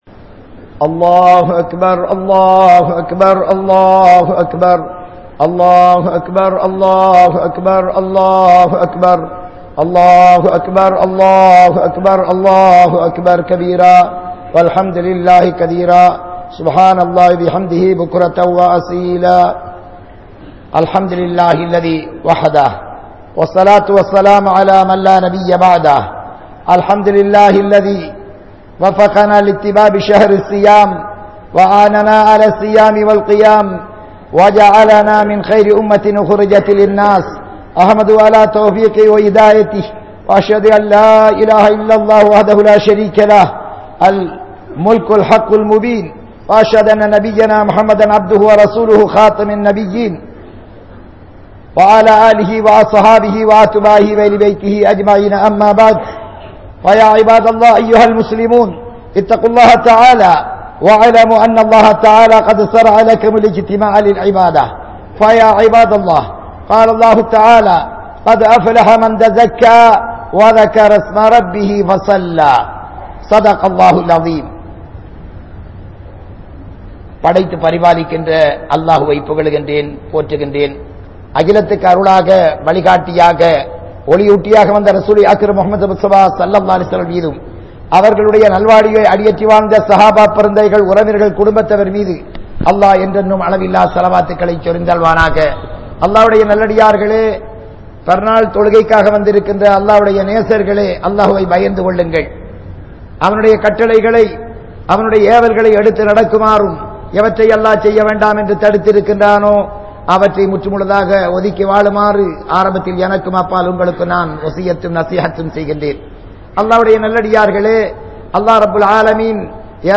Eid Ul Fithr Bayan | Audio Bayans | All Ceylon Muslim Youth Community | Addalaichenai